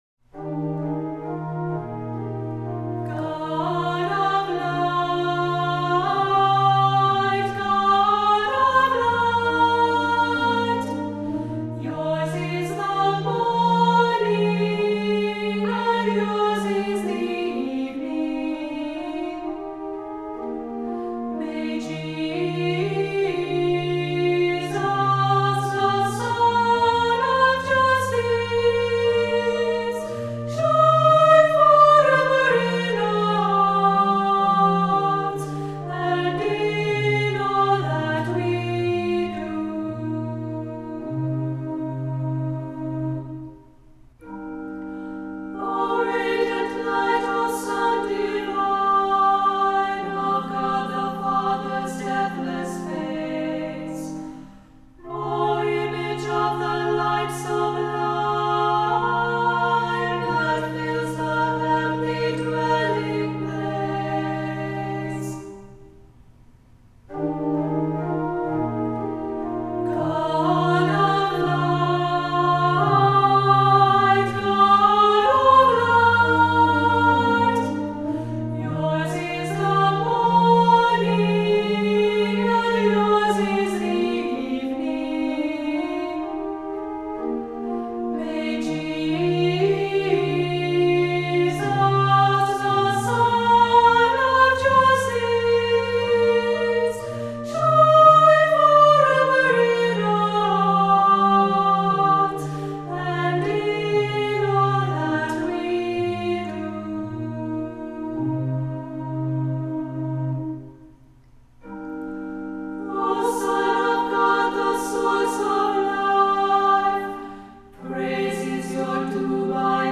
Voicing: Unison ChildrenÕs Choir, descant